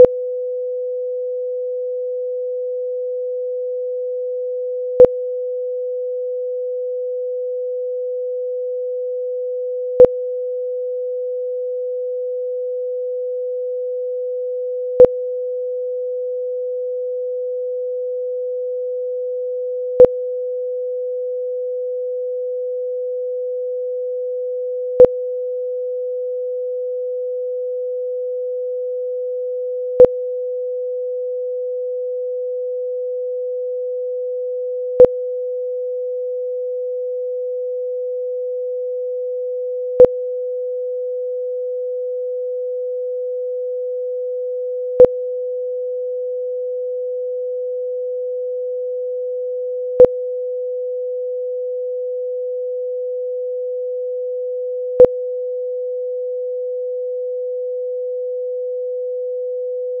audio burst refused to show a +20 dB level. It turned out that I had enabled the compressor in the MP3-player that I used as source: